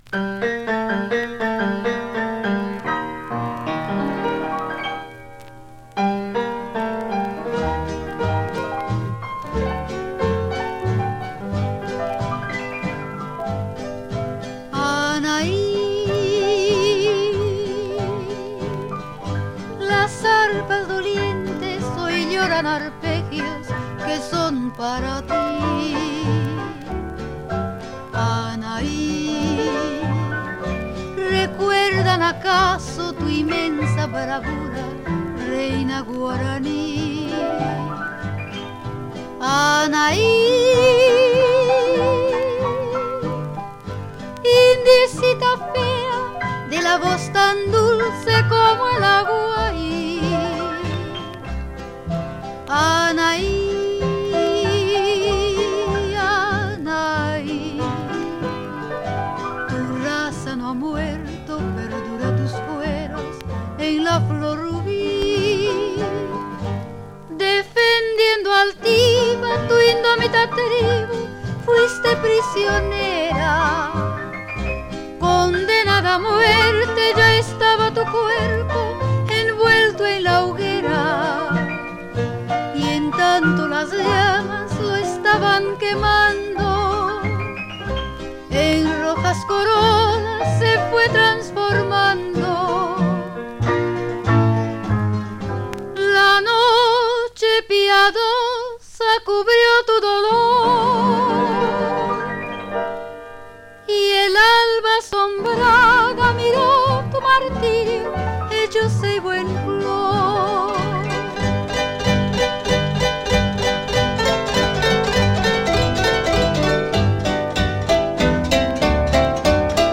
harp